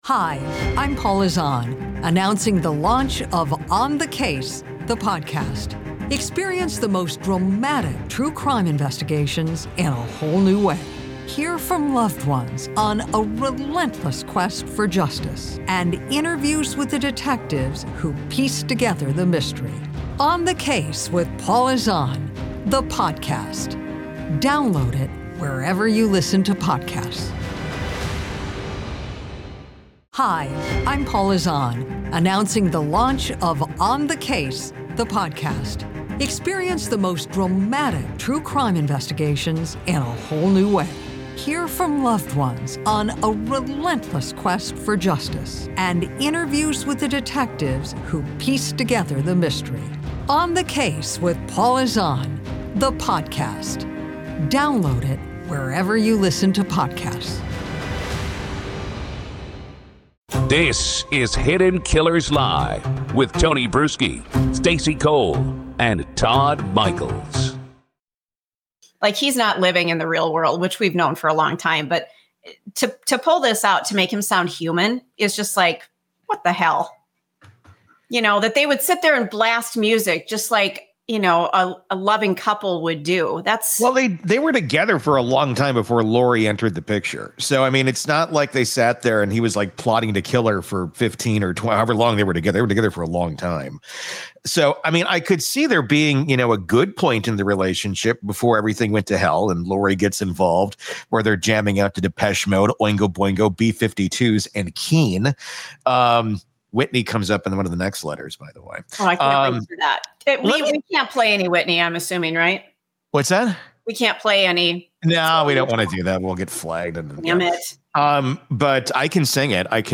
The hosts cut through the fiction, pointing out how convenient it is for Chad to frame Tammy’s death as “natural” when the autopsy showed signs of asphyxiation.